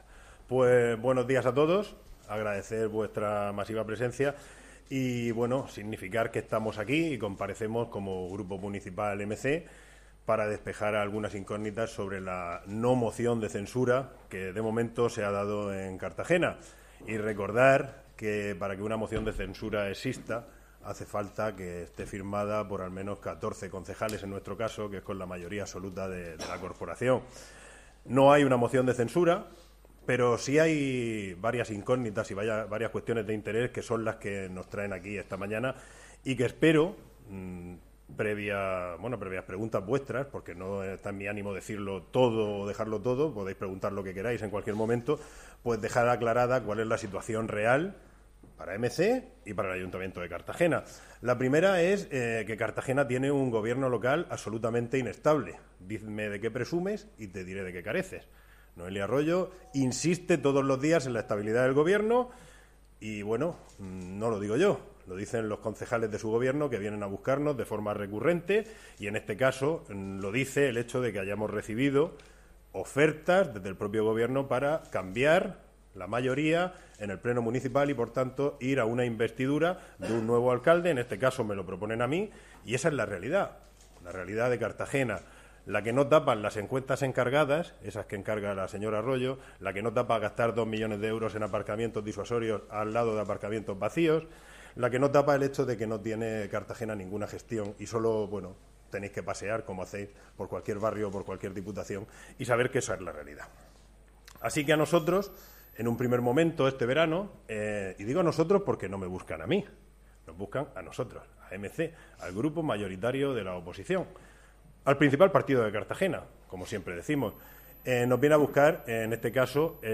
Audio: Audio Rueda de Prensa. (MP3 - 18,35 MB)
El portavoz de MC Cartagena, Jesús Giménez Gallo, ha comparecido esta mañana en rueda de prensa para esclarecer las informaciones que han aparecido en los últimos días sobre una presunta moción de censura. El líder de la oposición ha puesto sobre la mesa la evidente fragilidad De Arroyo y el descontento de los concejales de su propio gobierno ante su autoritarismo.